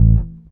Double Bass Short (JW2).wav